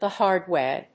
the hárd wày